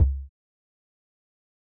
kick03.mp3